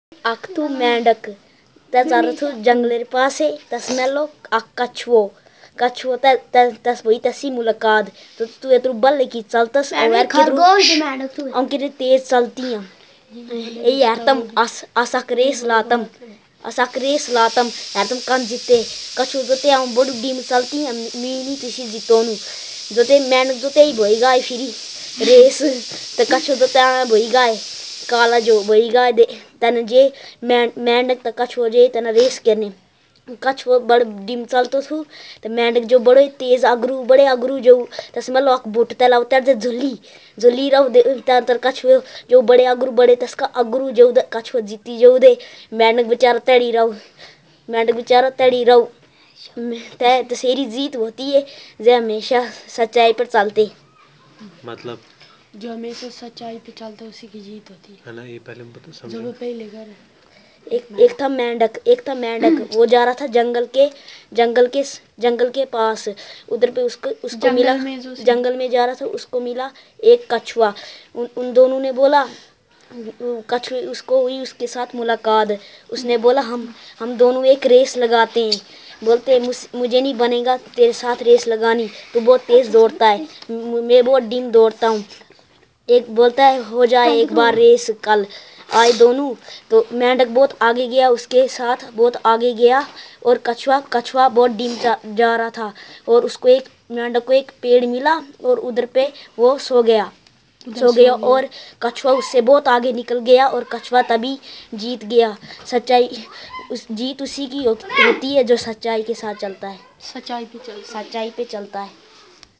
Conversation about inspirational story